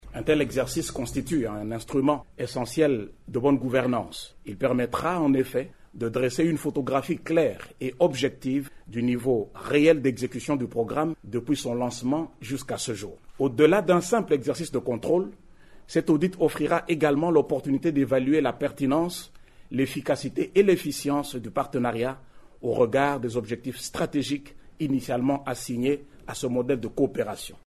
Devant la presse, le Directeur général de cette structure de l’Etat, Freddy Yodi Shembo indique que cette initiative vise à renforcer la transparence, la crédibilité et la redevabilité dans la mise en œuvre du partenariat entre les parties chinoise et congolaise.